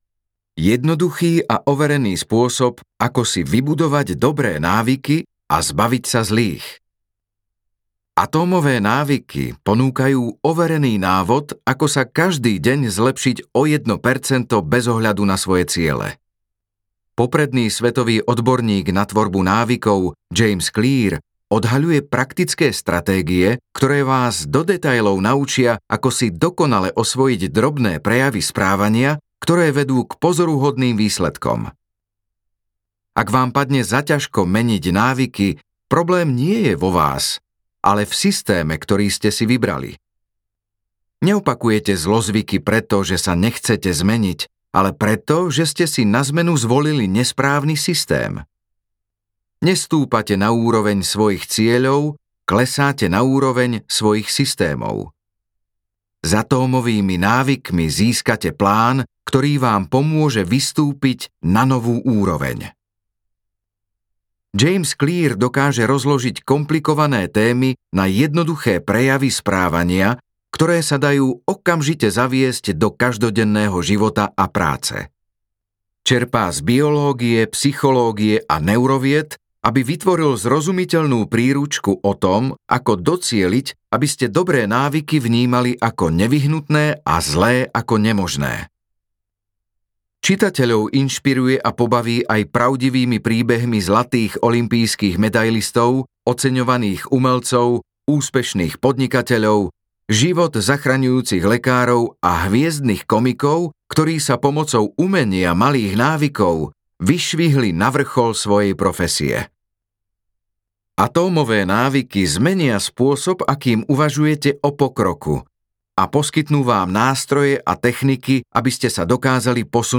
Atómové návyky audiokniha
Ukázka z knihy